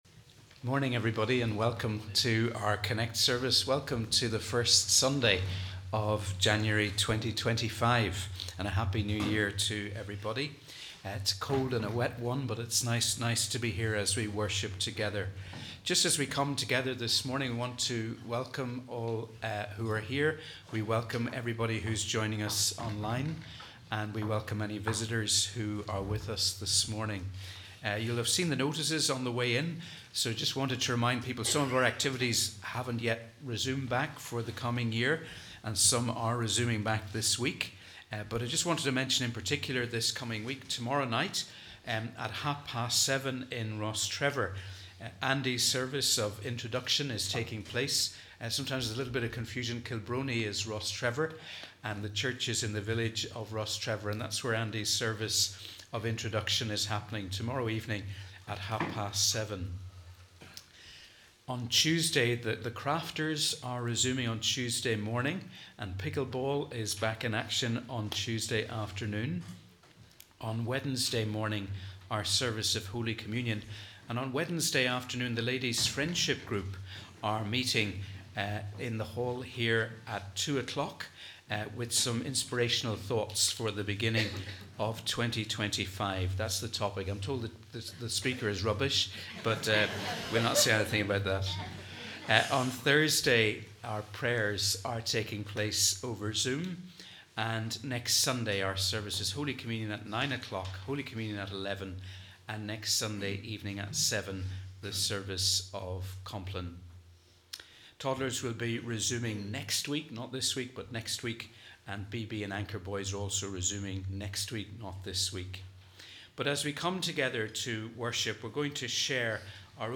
We warmly welcome you to our CONNEC+ service as we worship together on the 2nd Sunday of Christmas